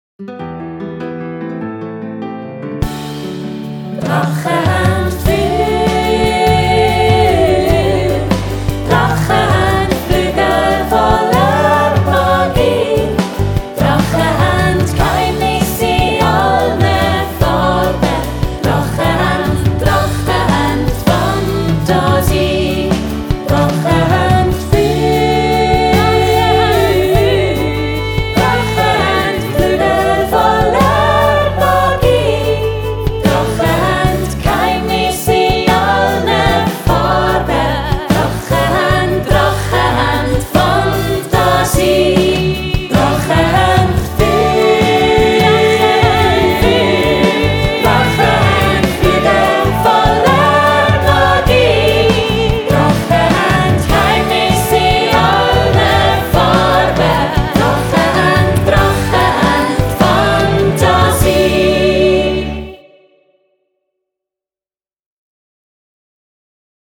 Ein feuriges, farbenfrohes und fantasievolles MärliMusical.